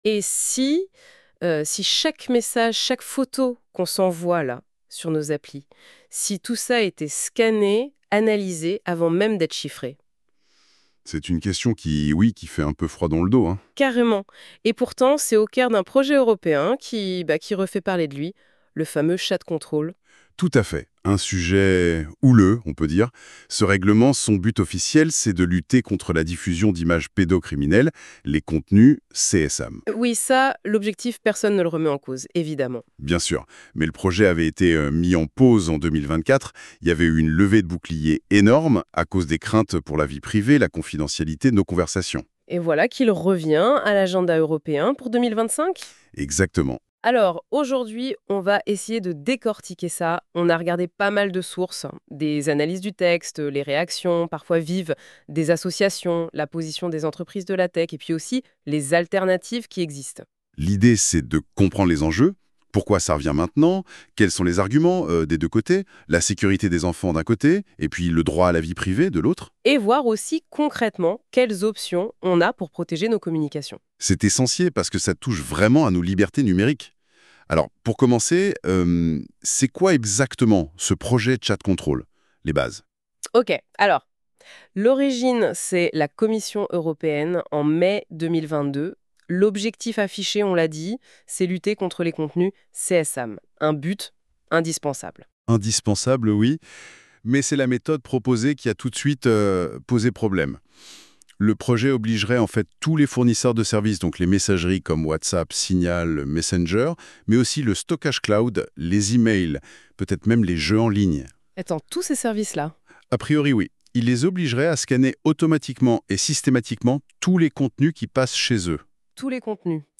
Nous avons intégré un podcast généré automatiquement grâce à NotebookLM, un outil d’intelligence artificielle.